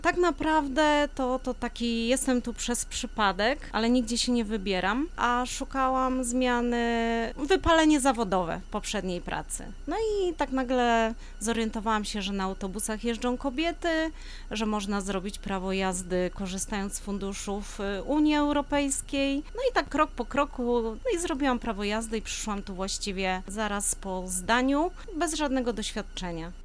Radio Rodzina odwiedziło zajezdnię autobusową przy ul. Obornickiej i porozmawiało o tych i innych tematach z pracownikami MPK Wrocław.